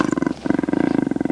ROAR.mp3